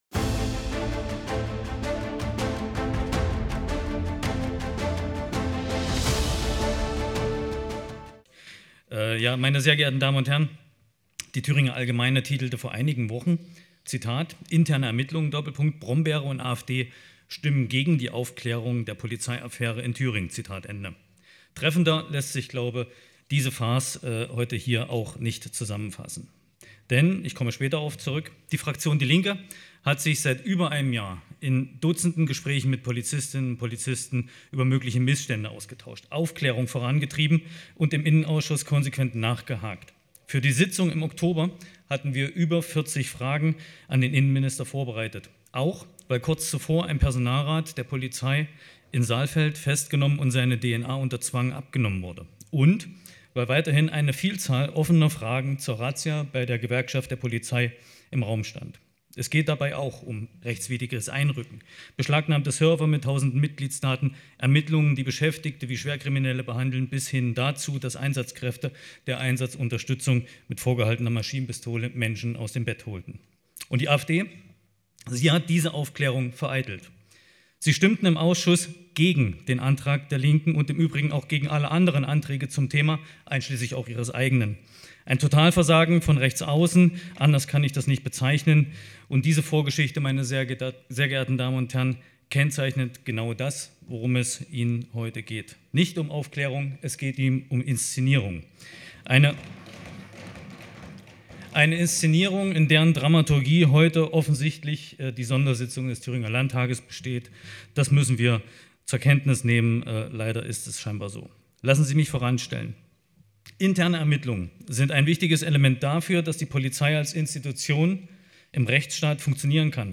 Im Studio erzählen die Autoren vom Entstehen des Buches, das als bloße Schreibübung angefangen hat und sich schließlich zu einem durchaus lesenswerten, kurzweiligen Roman entwickelt hat. Der Talk beschreibt den Entstehungsprozess des Romans, das, was während des Schreibens in den Autoren vorging, aber auch, wie die Leser der „Spatzenmuse“ auf die überraschende Wendung reagiert haben.